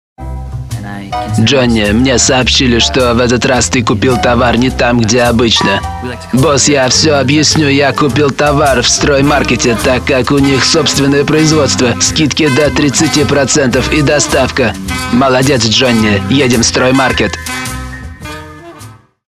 Делаю озвучку (пародия) голосом переводчика из 90-х.)
Тракт: Микрофон Shure KSM 44, звуковая карта Focuserite Saffire 6